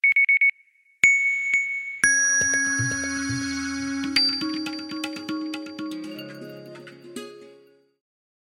Alarm_Beep_Taal.ogg